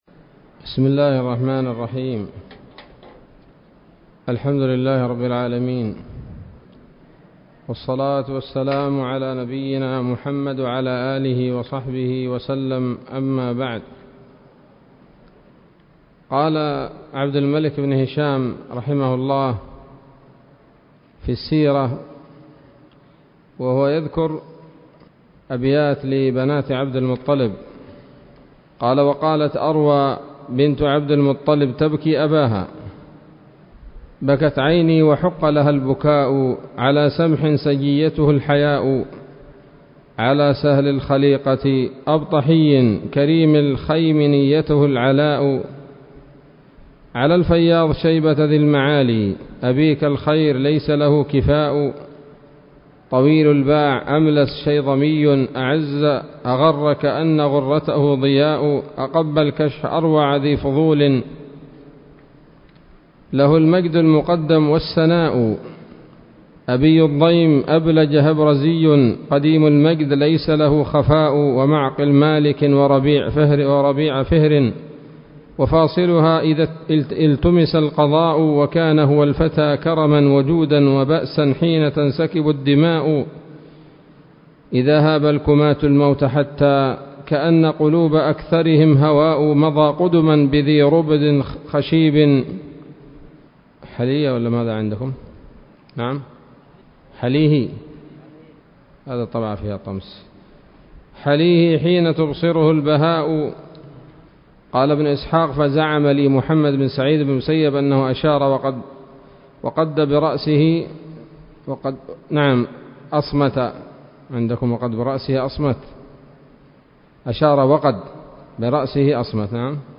الدرس الرابع عشر من التعليق على كتاب السيرة النبوية لابن هشام